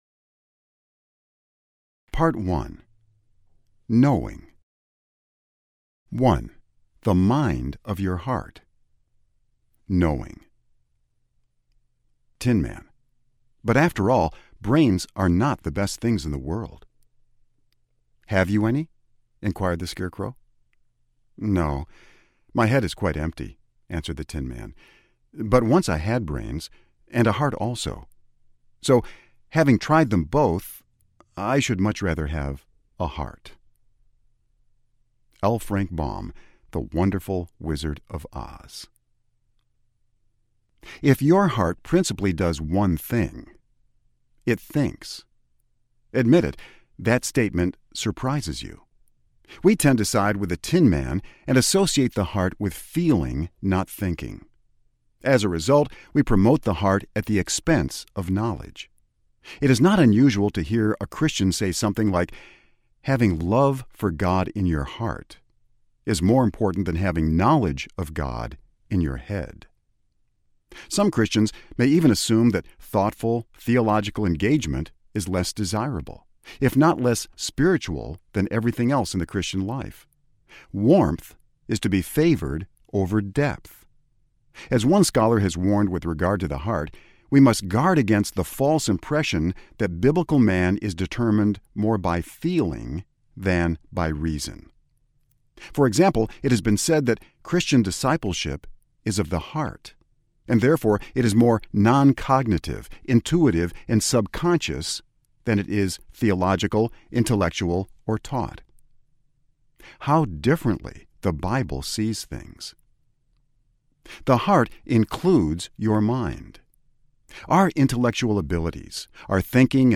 With All Your Heart Audiobook
Narrator
6.5 Hrs. – Unabridged